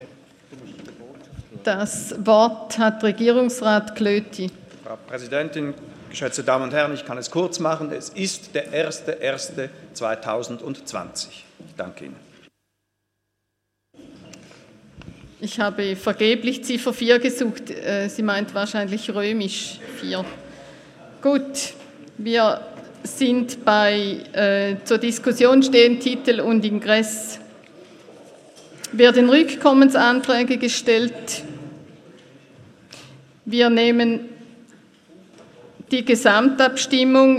Session des Kantonsrates vom 26. bis 28. November 2018